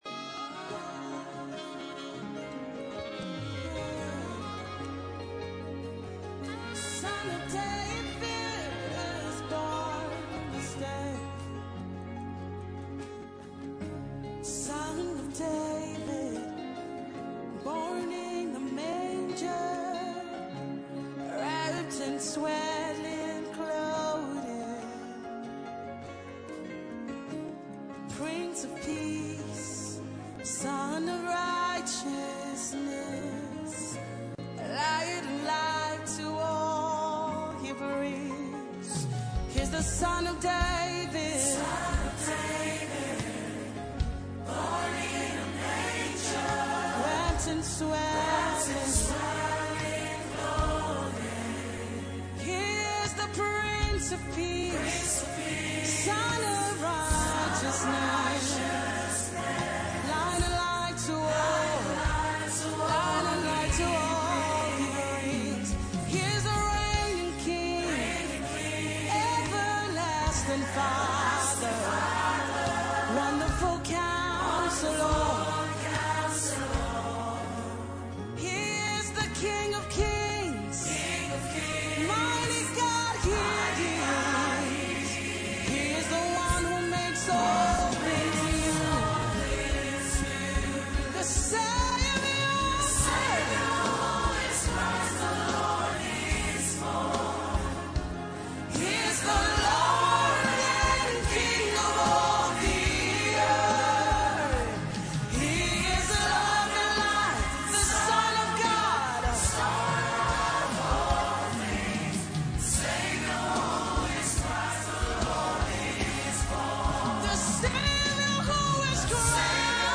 MORE CHRISTMAS SONGS/CAROLS
What an atmosphere of praise and worship!